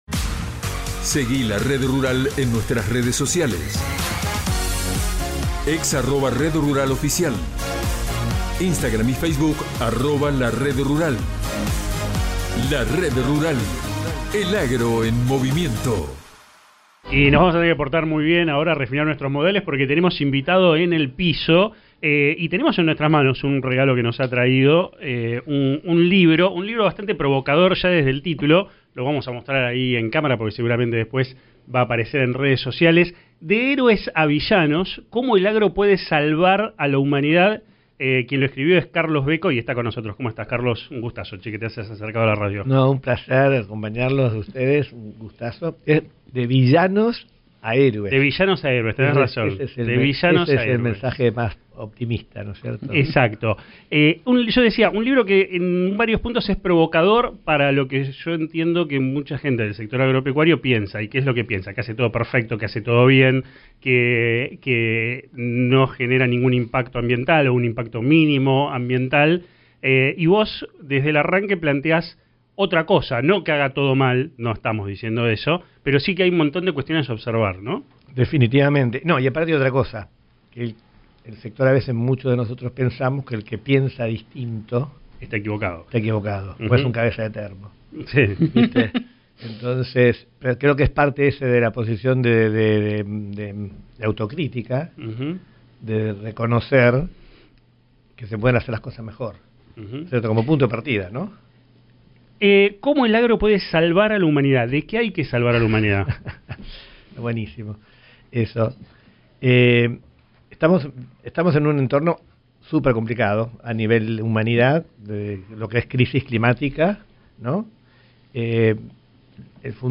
Entrevista en La Red Rural
La bronca de uno de los entrevistadores